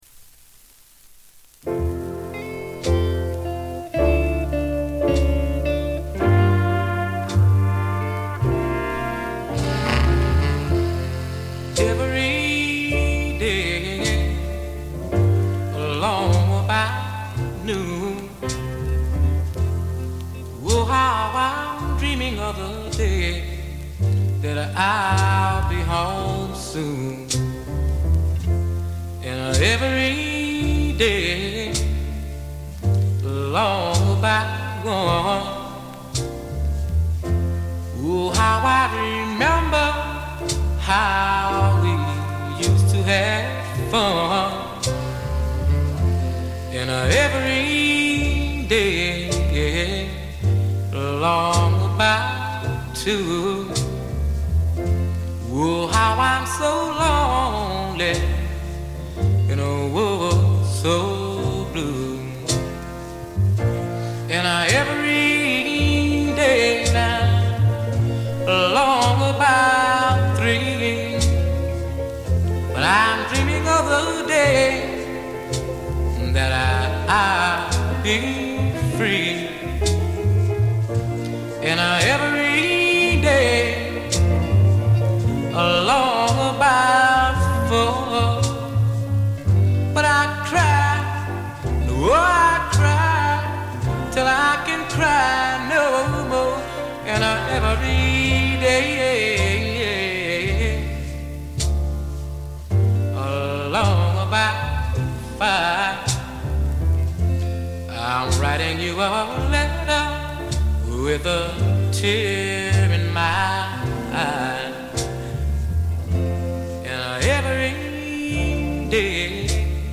静音部で微細なバックグラウンドノイズが聴かれる程度。
モノラル盤。
試聴曲は現品からの取り込み音源です。